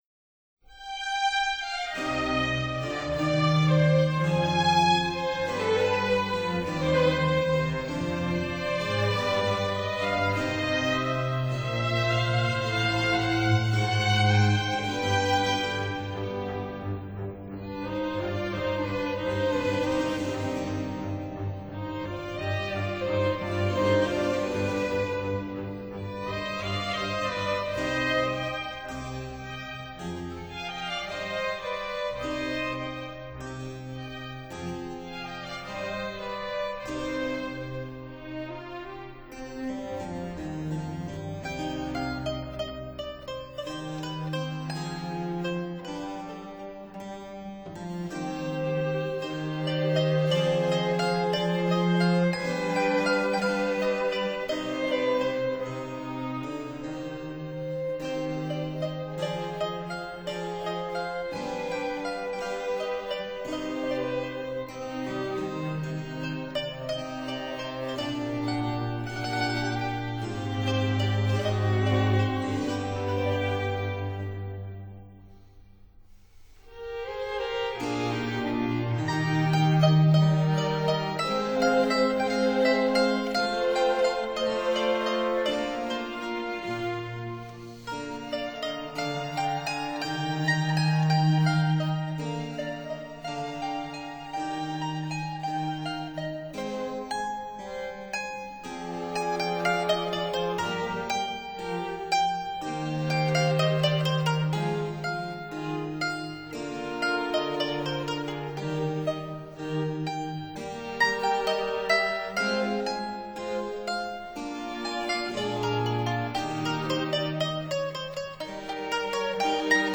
Concertos for mandolin, strings and continuo
modern instruments
mandolin